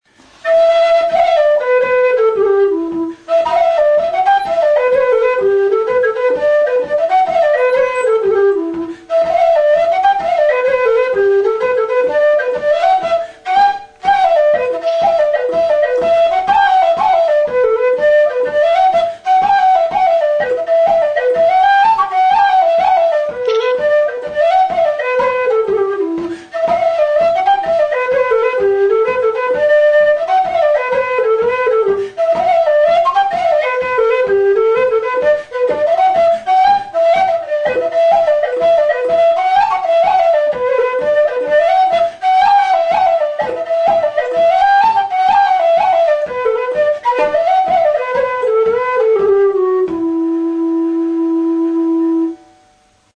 THE EXILE'S JIG (Slip-Jig).
LOW 'RE' WHISTLE
Bi eskuko flauta zuzena da, aluminiozkoa.
Re tonuan afinaturik dago.